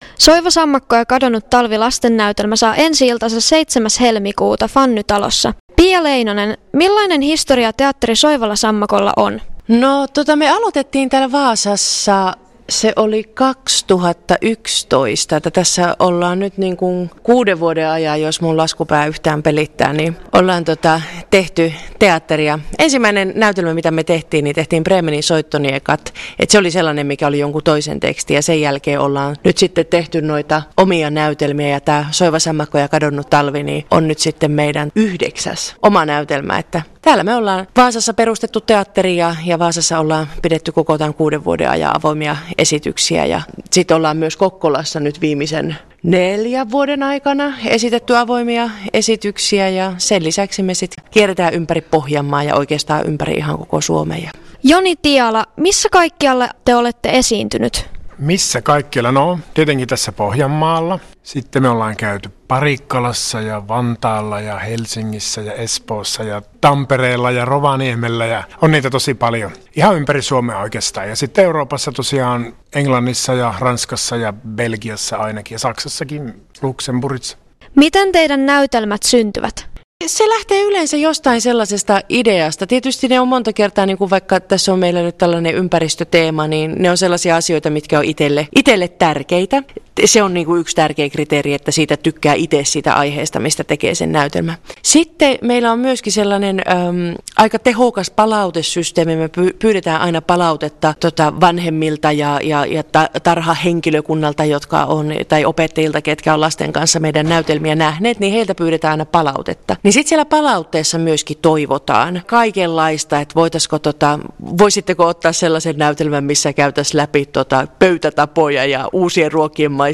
Teatteri